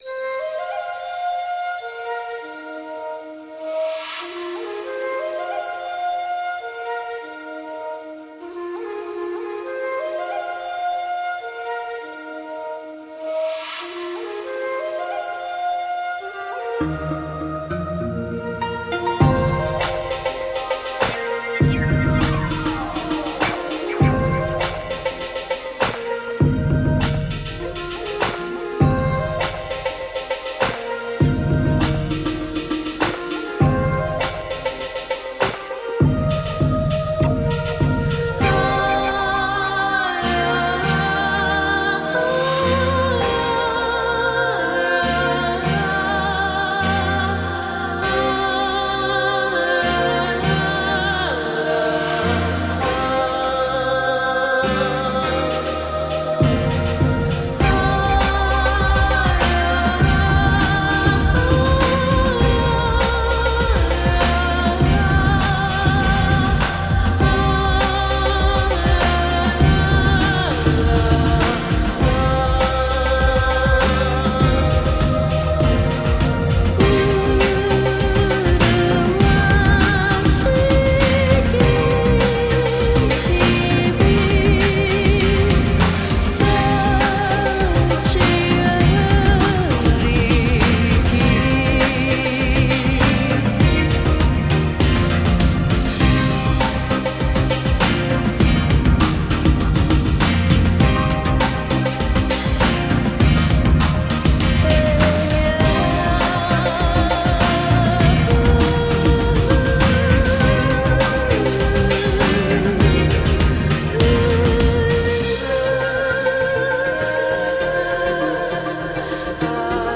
A world of independent music with electronic spice.
A mix of world music and contemporary electronica.
Tagged as: World, Folk